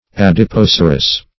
Adipocerous \Ad`i*poc"er*ous\, a. Like adipocere.